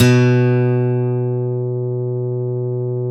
Index of /90_sSampleCDs/Roland - Rhythm Section/GTR_Steel String/GTR_ 6 String
GTR 6-STR30R.wav